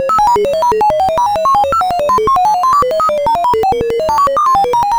computerNoise_002.ogg